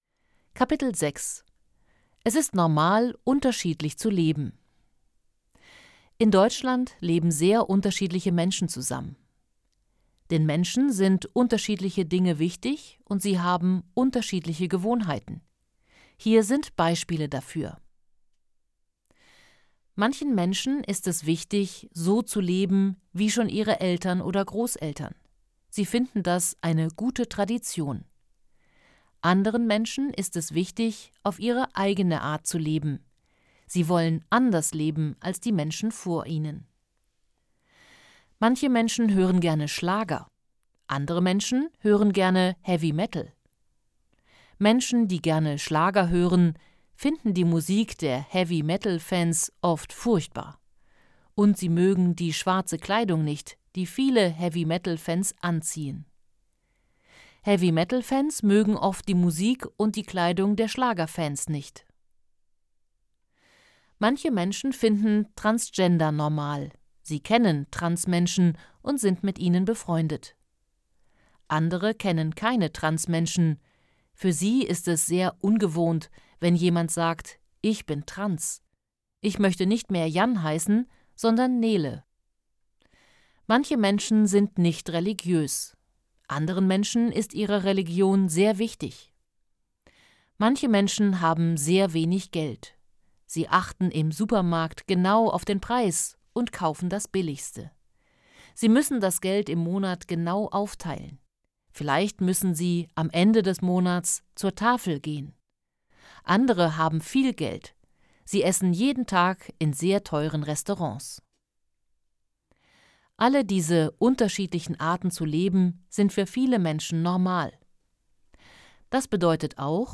Kapitel 6: Es ist normal, unterschiedlich zu leben Hörbuch: „einfach POLITIK: Zusammenleben und Diskriminierung“
• Produktion: Studio Hannover